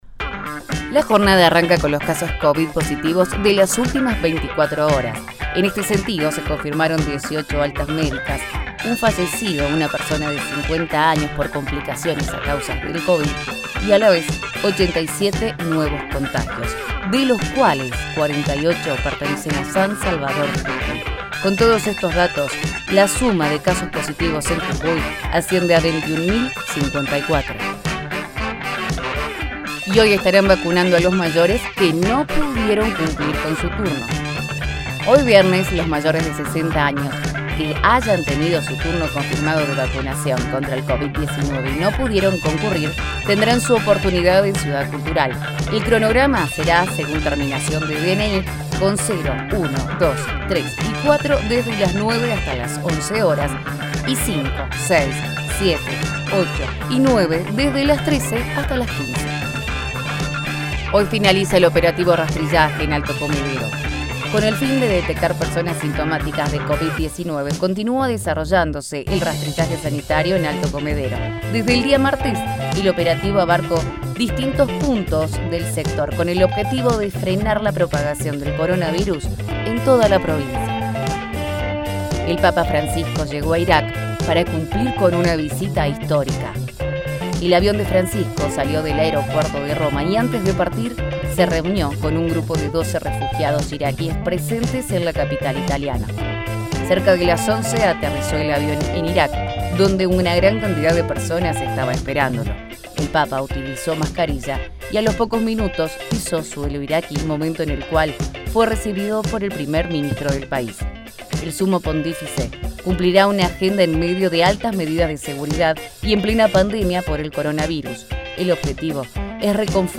PODCAST CON MUSICA.mp3